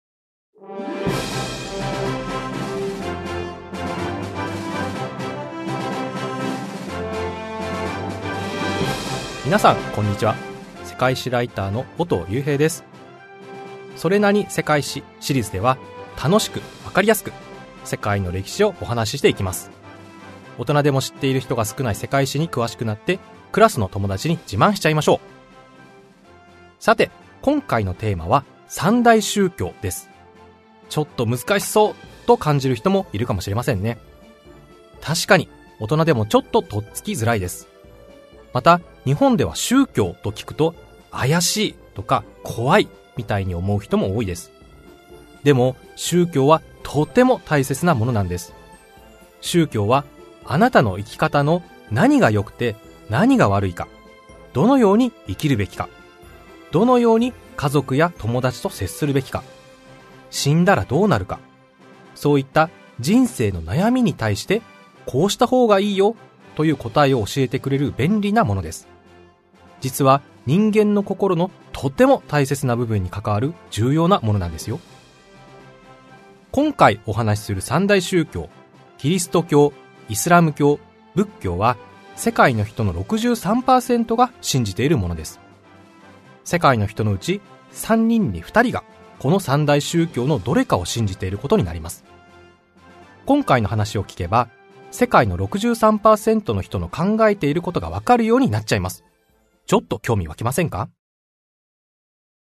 [オーディオブック] 小学生のうちに知っておきたい！それなに？世界史 Vol.2 三大宗教